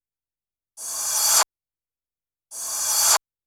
REVERSE HA-L.wav